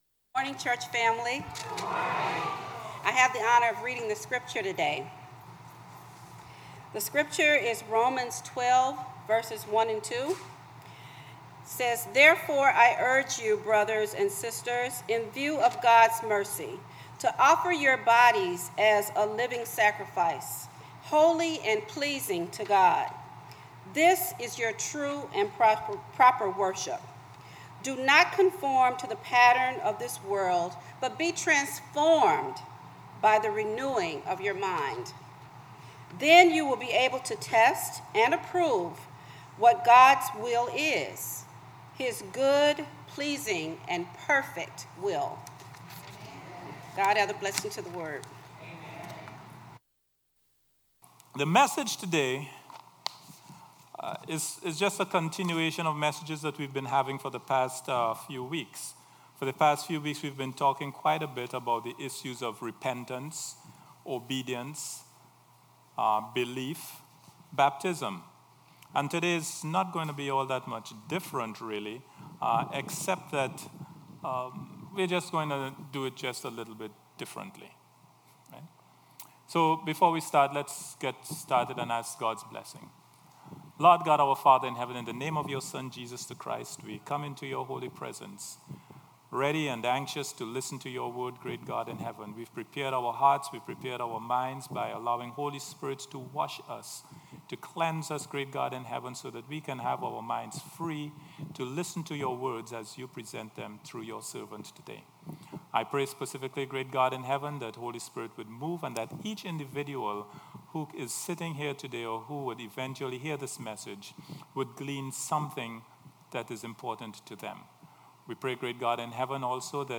Worship Service 7/23/17